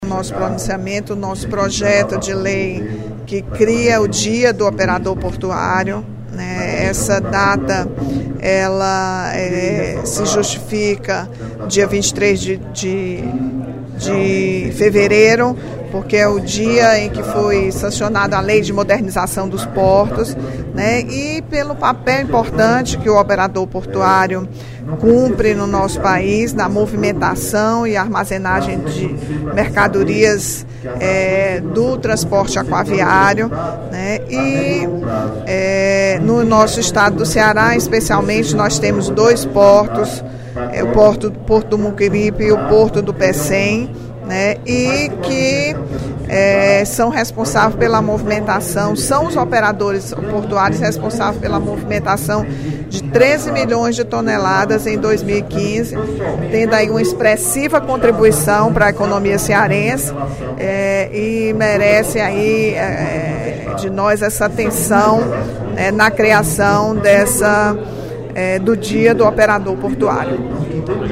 A deputada Rachel Marques (PT) defendeu, durante o primeiro expediente da sessão plenária desta terça-feira (23/02), a aprovação pelo Plenário do projeto de lei nº 7/16, de sua autoria, que tramita na Assembleia, instituindo o Dia Estadual do Operador Portuário, a ser celebrado em 23 de fevereiro.